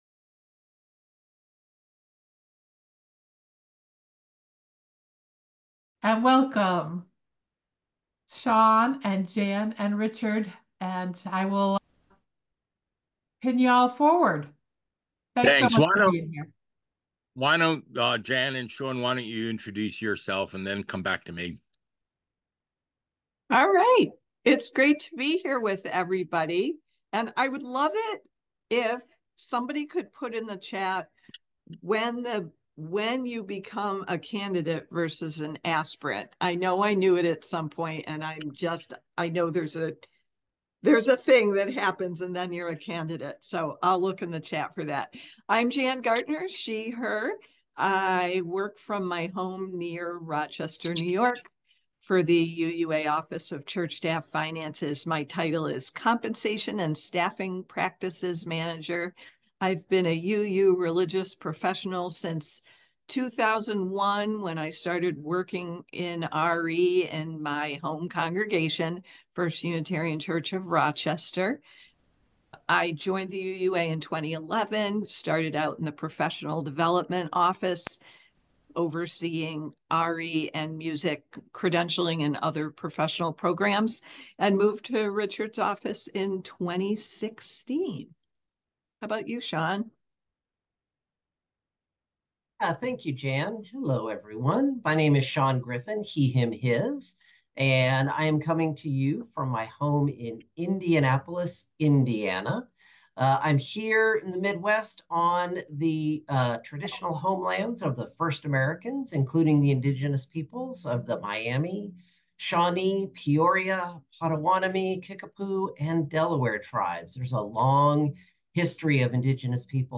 This week’s episode features an MFN Webinar from April 2024. Click here to listen to Show Me the Money .